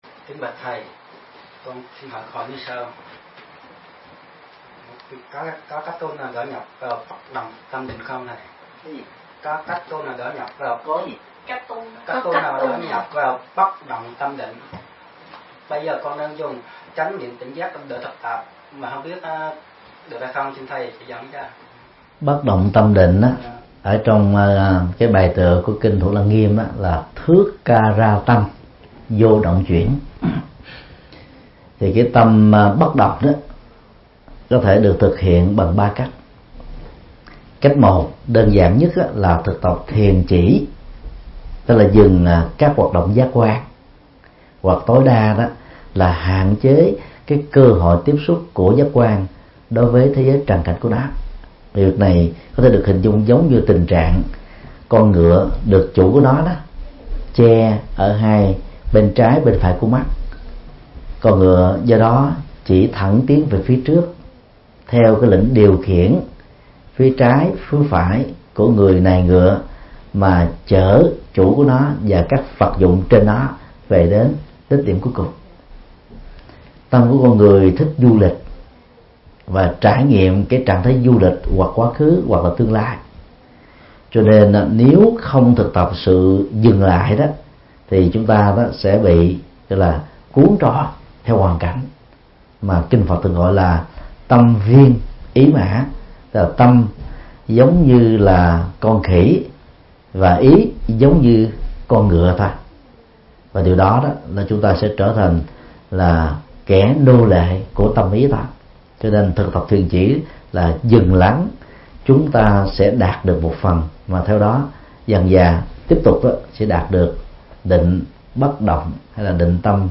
Vấn đáp